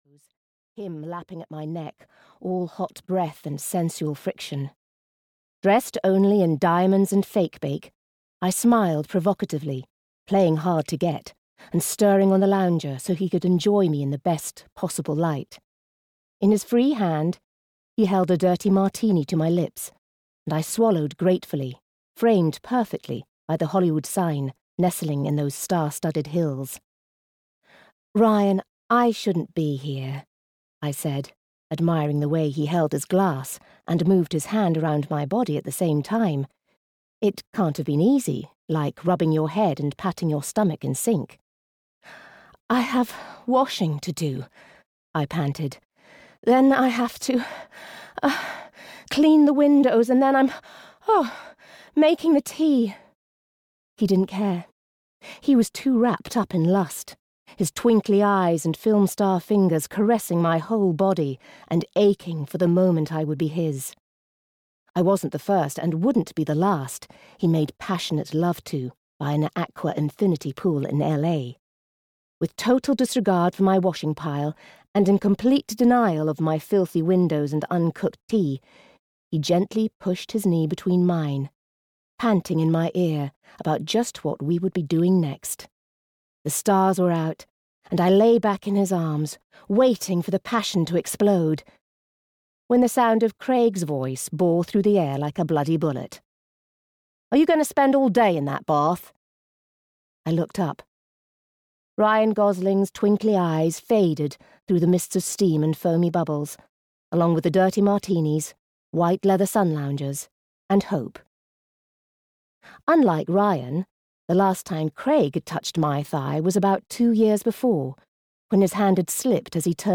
Love, Lies and Lemon Cake (EN) audiokniha
Ukázka z knihy